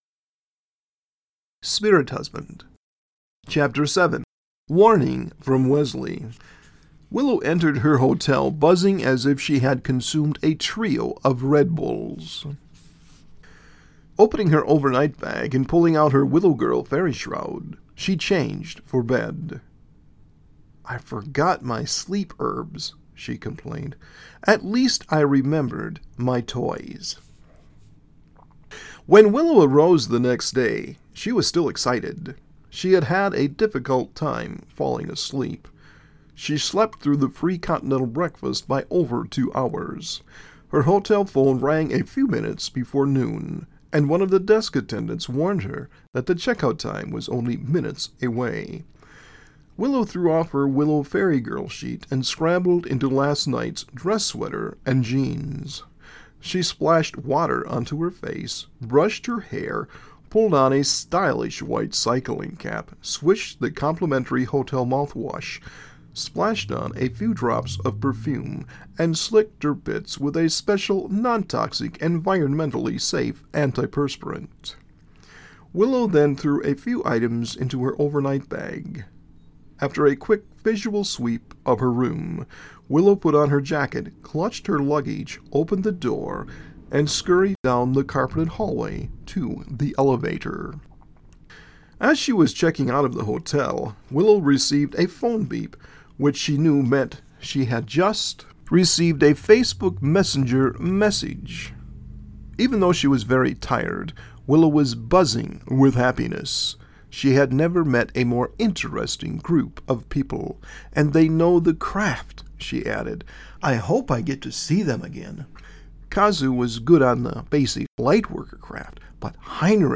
Audio Book Chapters for Spirit Husband